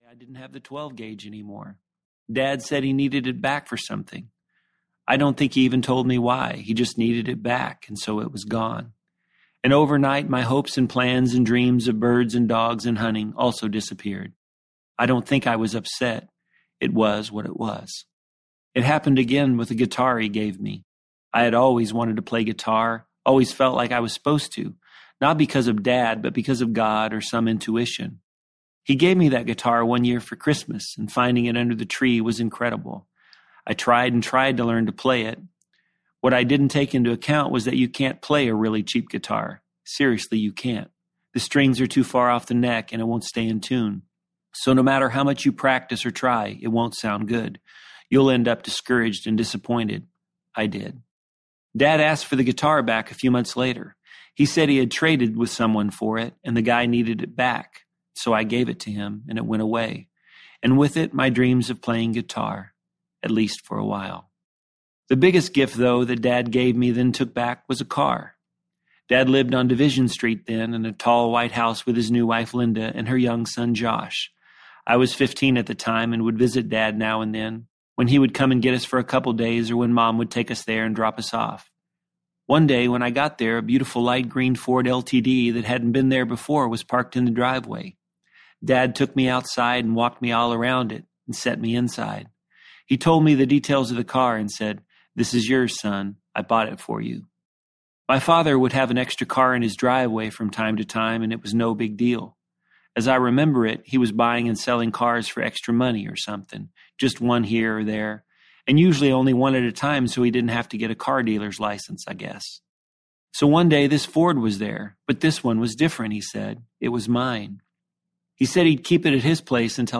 This Life I Live Audiobook
Narrator
Rory Feek